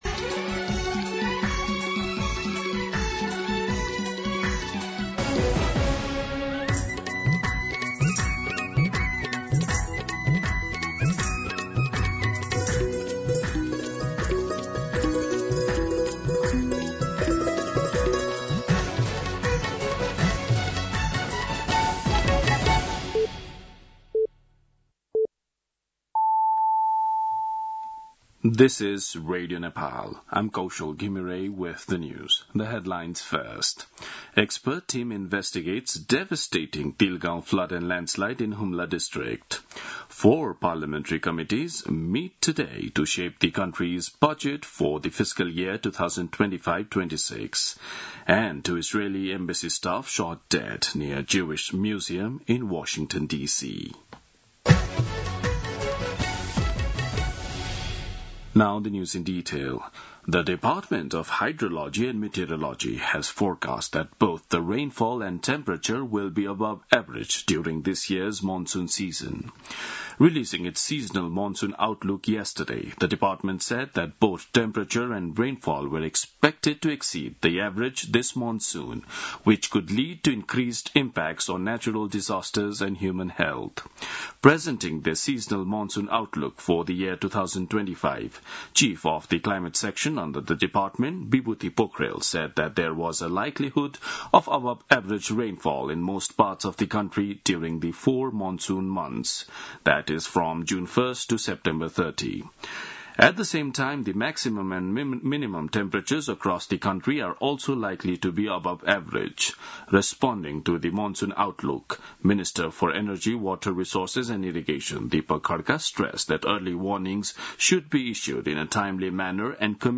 दिउँसो २ बजेको अङ्ग्रेजी समाचार : ८ जेठ , २०८२